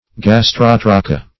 Search Result for " gastrotrocha" : The Collaborative International Dictionary of English v.0.48: Gastrotrocha \Gas*trot"ro*cha\, n. [NL., fr. Gr.
gastrotrocha.mp3